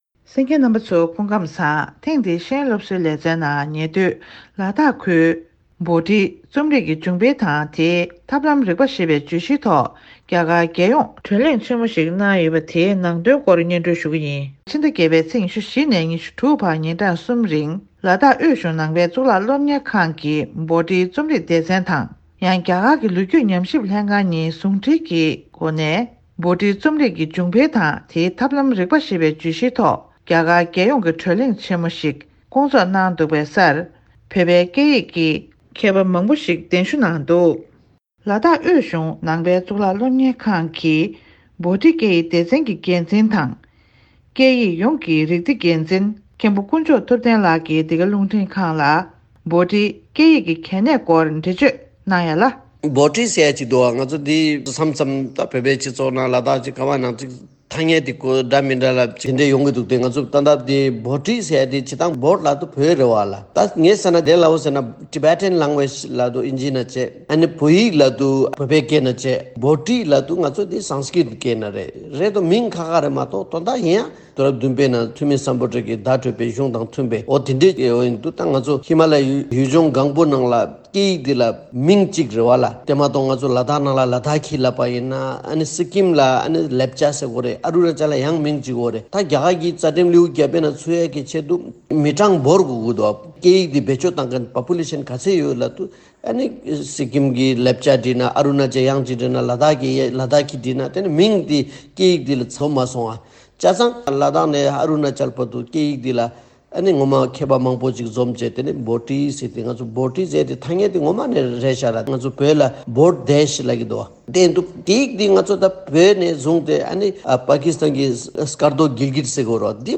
ཧི་མ་ལ་ཡའི་རི་བརྒྱུད་ས་ཁུལ་དང་བོད་པའི་མཁས་པ་མང་པོ་གདན་འདྲེན་ཞུས་ནས་བྷོ་ཊིའི་རྩོམ་རིག་གི་བྱུང་འཕེལ་དང་དེའི་ཐབས་ལམ་རིག་པ་ཞེས་པའི་བརྗོད་གཞིའི་ཐོག་བགྲོ་གླེང་གནང་བ།